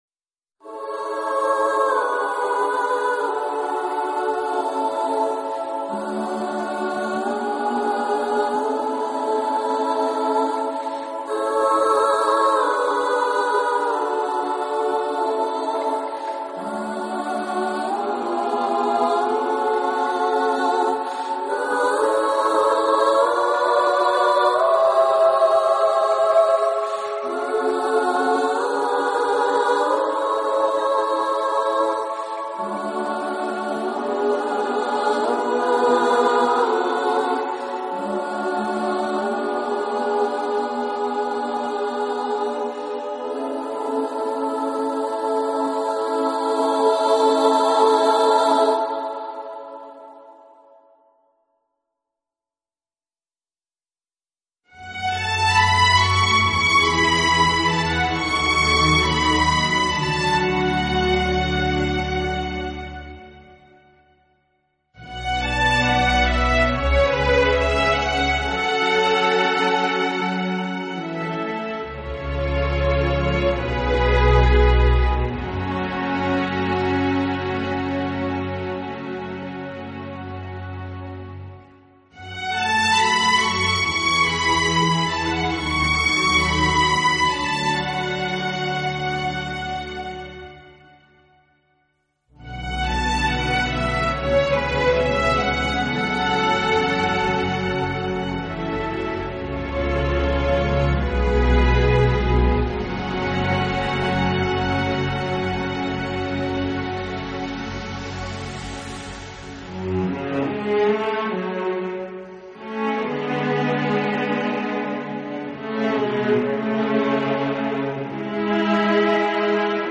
音乐类型: 轻音乐
唯美风格，华音曼妙，激情澎湃。
绝对真实的乐器演奏，让您摆脱midi、电子音乐的困扰，享受一次完美的听觉饕餮。